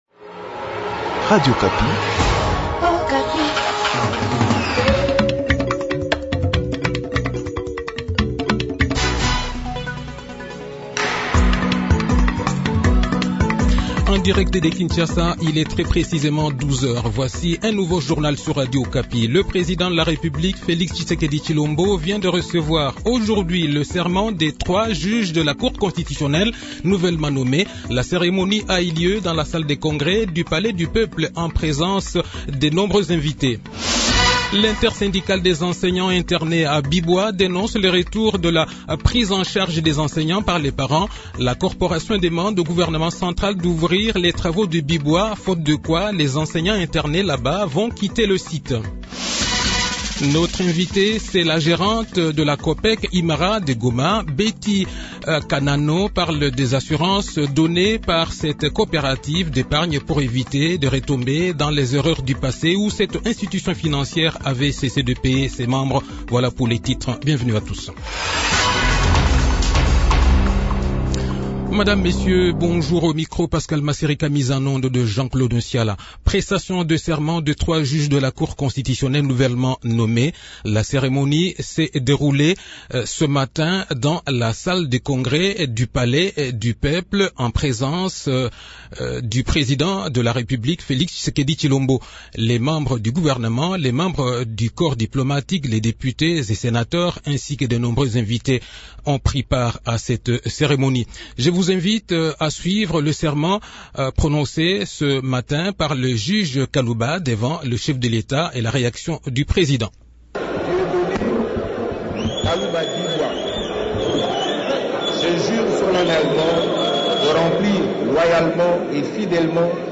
Le journal-Français-Midi
Conducteur du journal de 12 h, 21 Octobre 2020